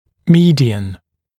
[‘miːdɪən][‘ми:диэн]средний, срединный, центральный, занимающий срединное положение